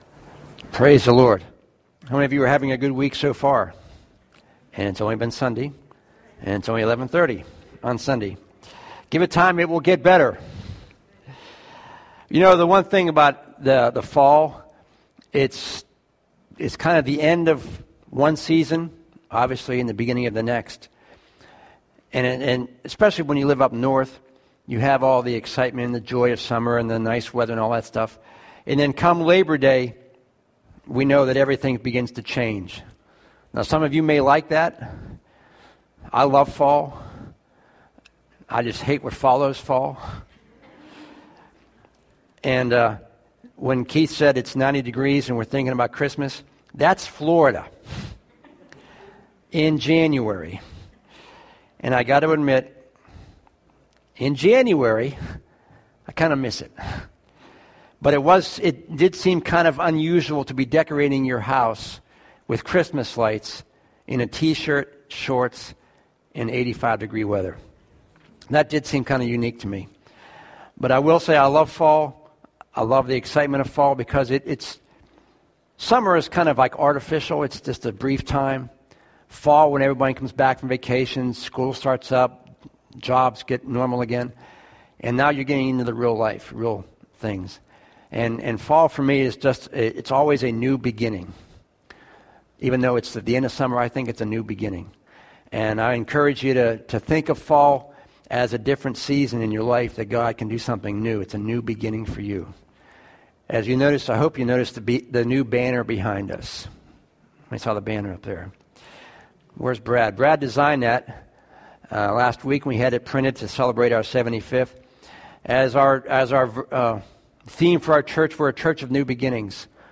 This week's sermon continued our series talking about the seven churches found in Revelation 2-3. Today's sermon picked up where we left off two weeks ago, talking about the church in Sardis.